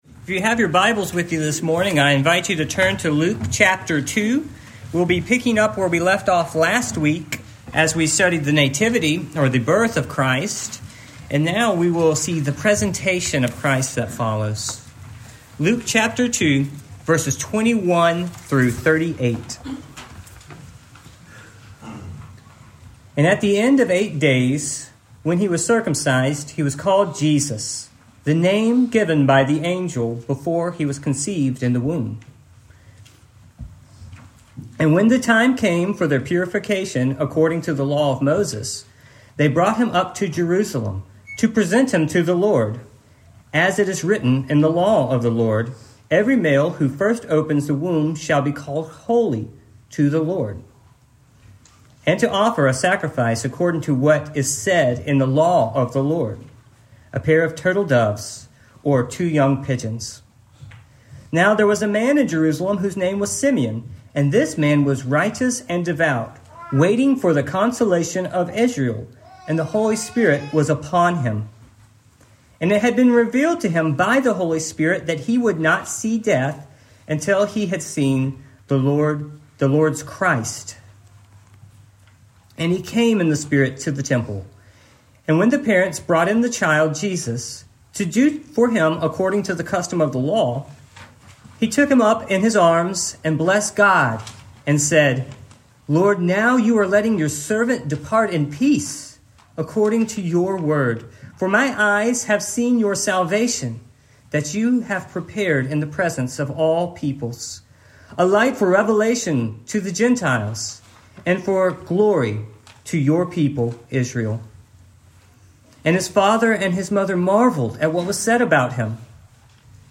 Luke 2:21-38 Service Type: Morning Main Point